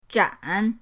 zhan3.mp3